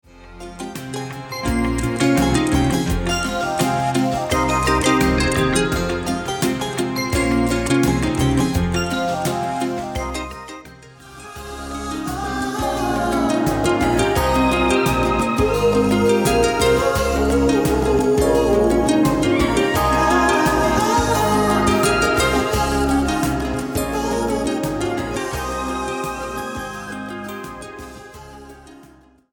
Recorded spring 1986 at the Sinus Studios, Bern Switzerland